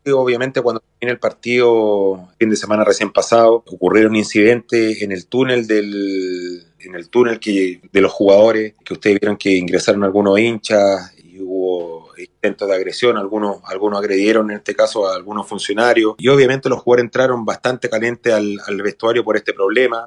Así lo detalló en diálogo con el programa Dragón Deportivo de la Radio La Nueva Super